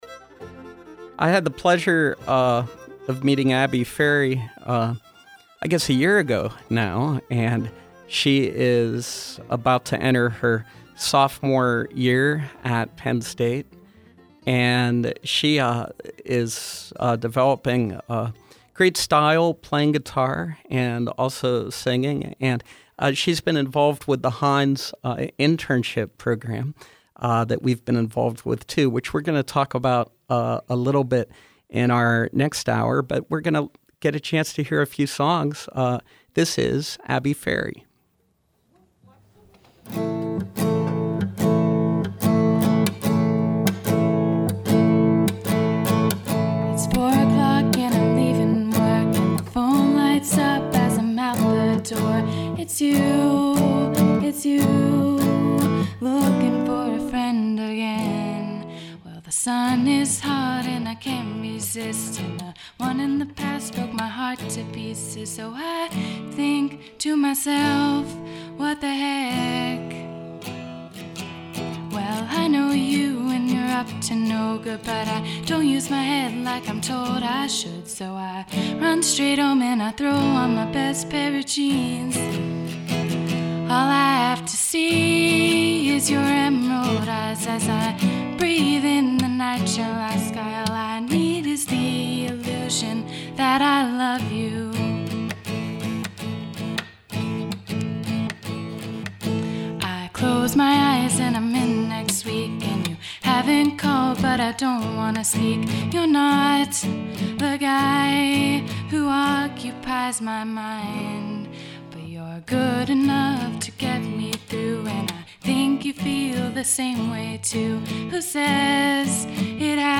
We’re pleased to host her debut broadcast performance, live on SLB.